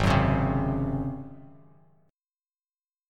G#11 chord